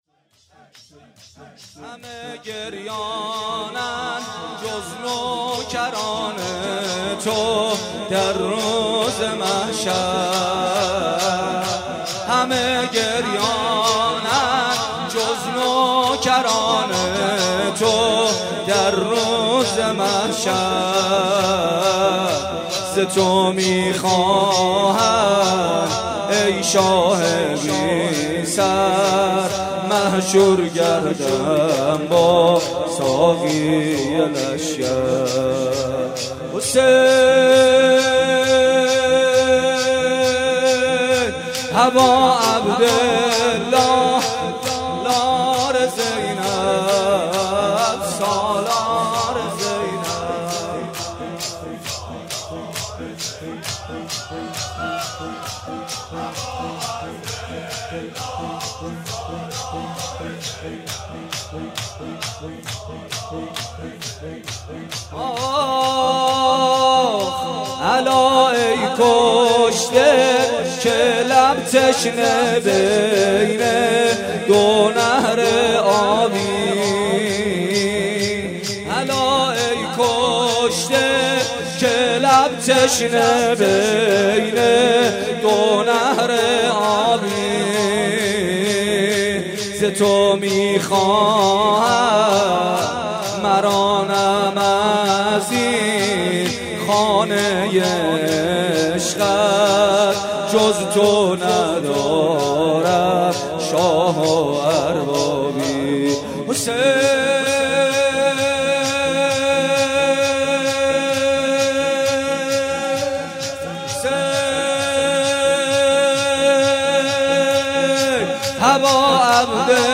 ولادت امام سجاد (ع) 96 - مسجد گیاهی - شور - همه گریانند
ولادت امام سجاد (ع)
شور محمد حسین پویانفر